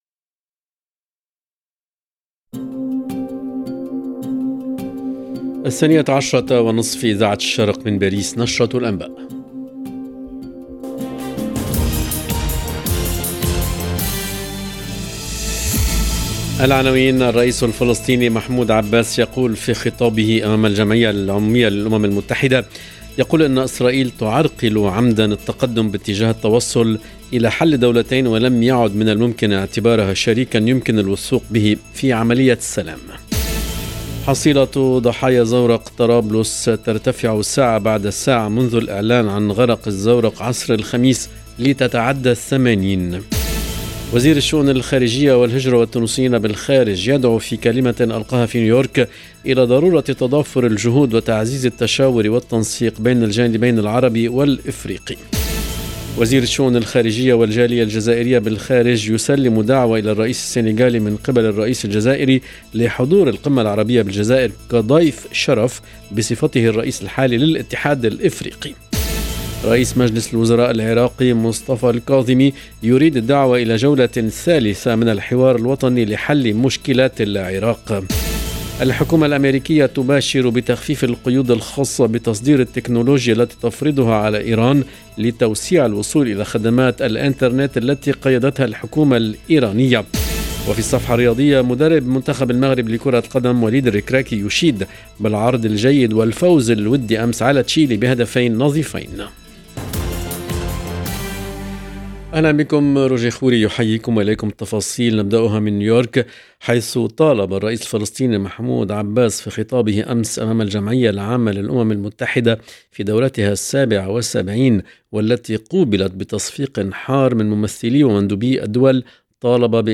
LE JOURNAL EN LANGUE ARABE DE MIDI 30 DU 24/09/22